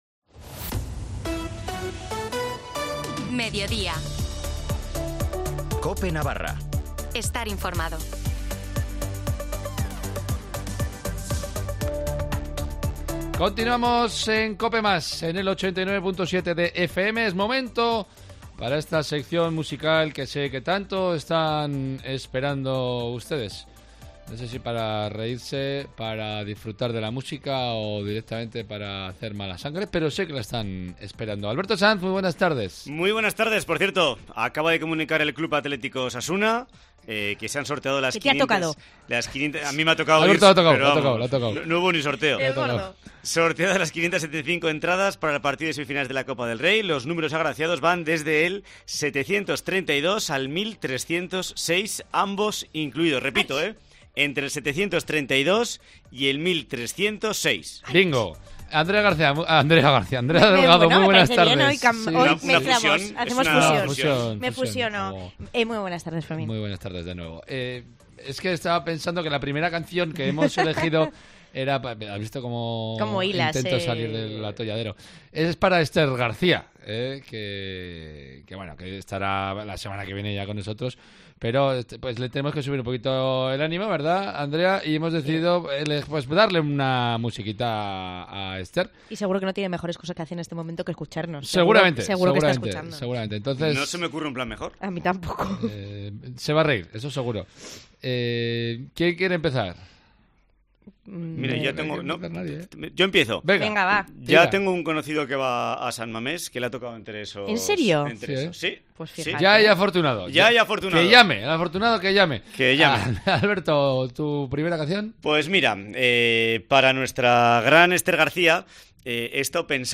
En la sección del 24 marzo tocamos tres temas